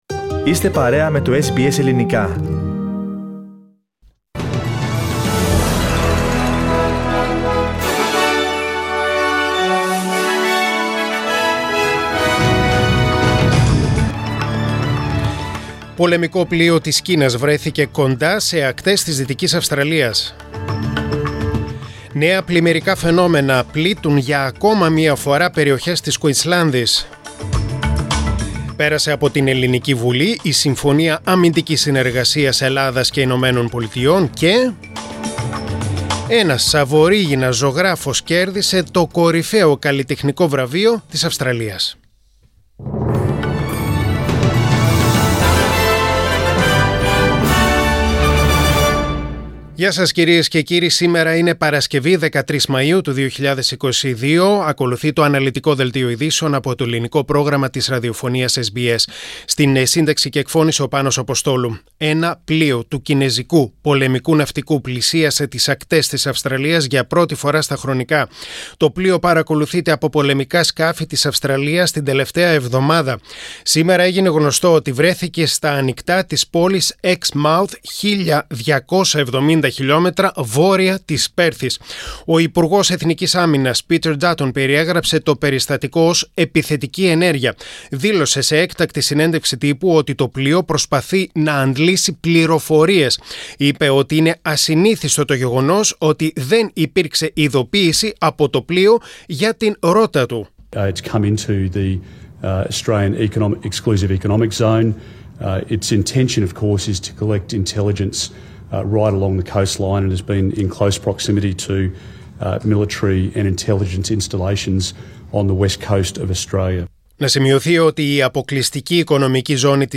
Δελτίο Ειδήσεων: Παρασκευή 13.5.2022